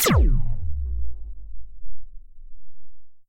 sounds_laser_06.ogg